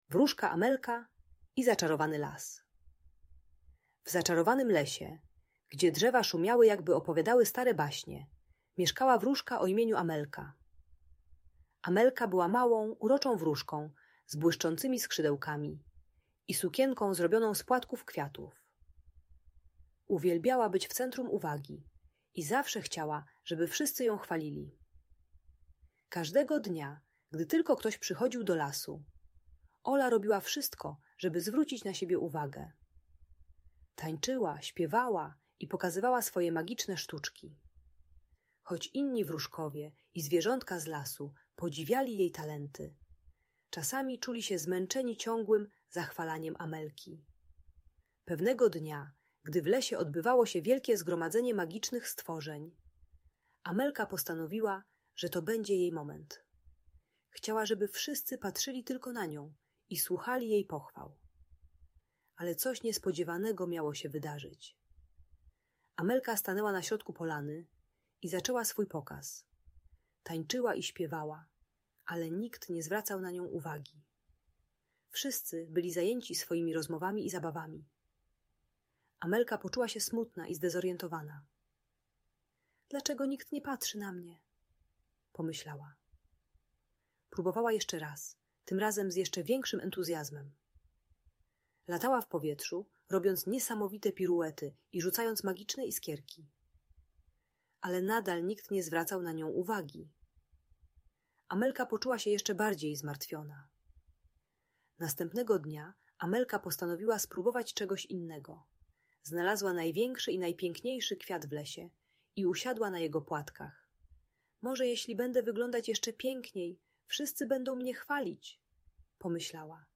Historia Wróżki Amelki i Zaczarowanego Lasu - Niepokojące zachowania | Audiobajka